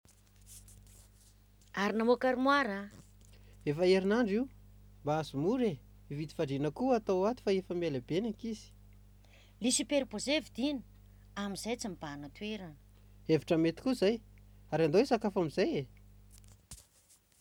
#08 : Conversation (suite)